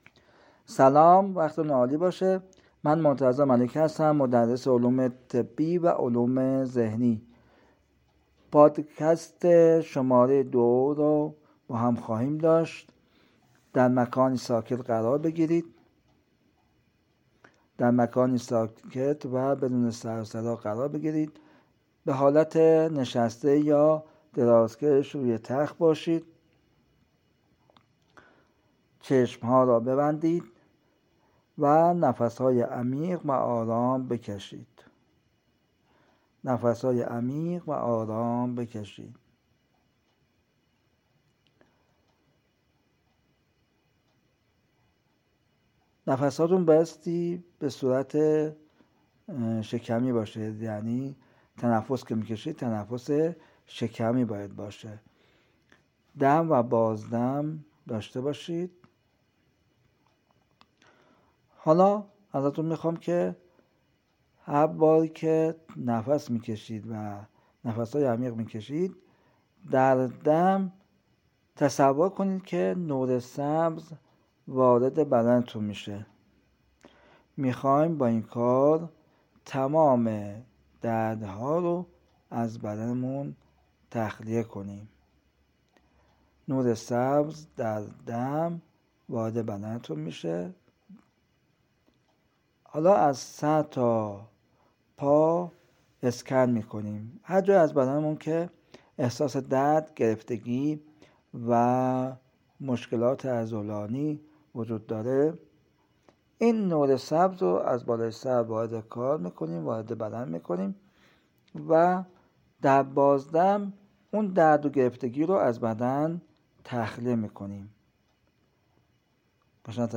مدیتیشن نور سبز